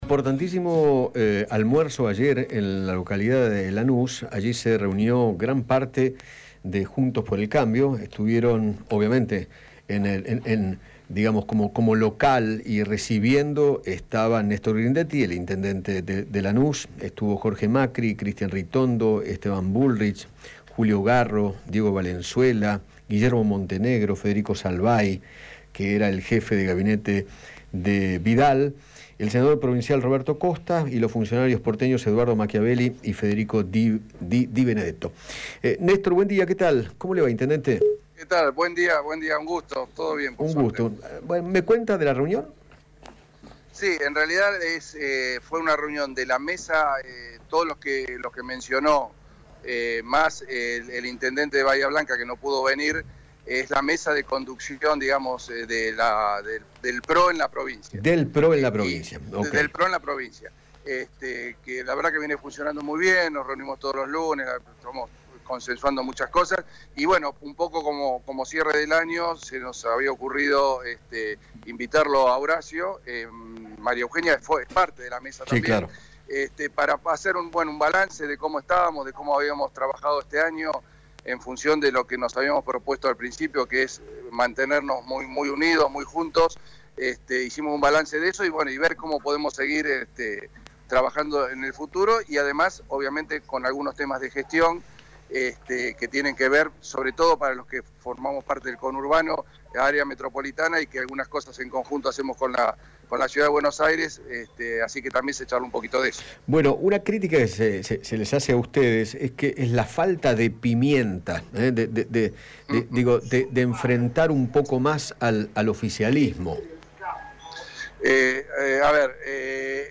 Néstor Grindetti, intendente de Lanús, dialogó con Eduardo Feinmann sobre la reunión que mantuvo la mesa de conducción del PRO en la provincia de Buenos Aires y se refirió a la situación en la que se encuentra su partido.